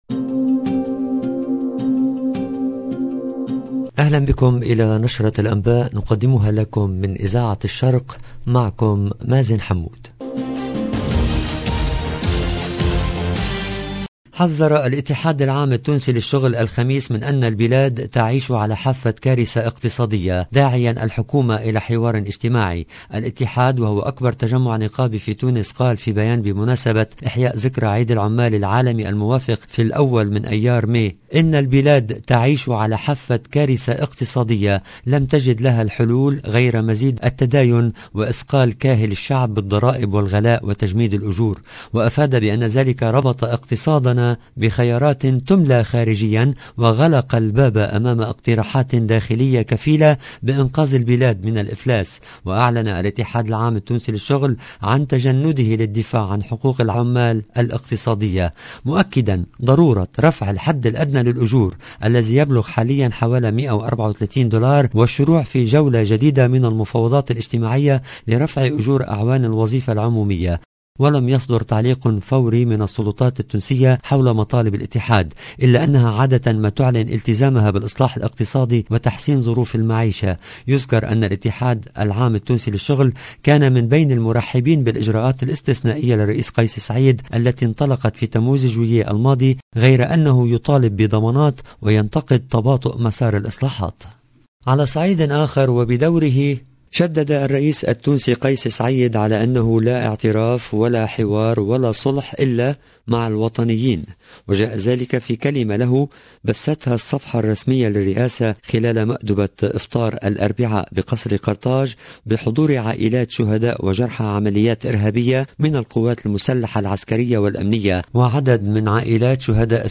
EDITION DU JOURNAL DU SOIR EN LANGUE ARABE DU 28/4/2022